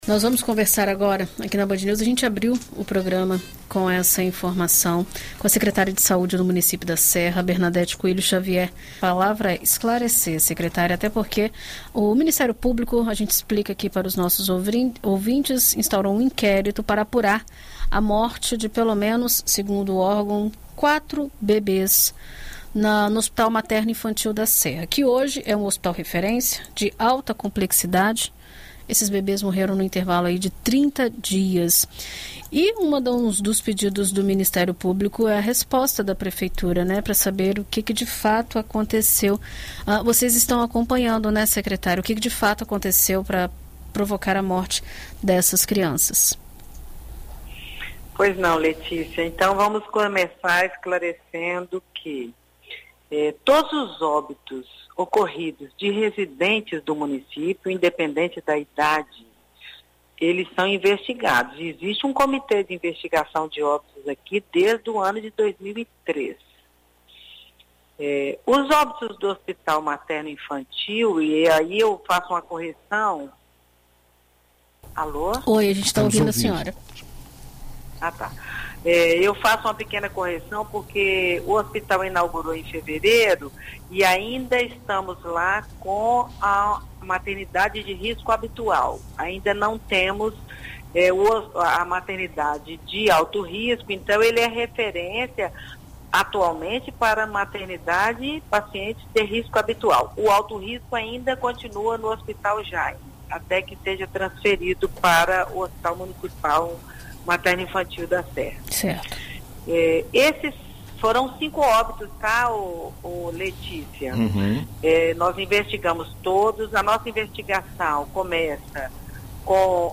Em entrevista à BandNews FM Espírito Santo na manhã desta sexta-feira (1°), a secretária de saúde da Serra, Bernadete Coelho Xavier, informou que o hospital já investiga o pré-natal e o prontuário das pacientes para apontar possíveis causas antes mesmo do laudo de verificação de óbito ser concluído.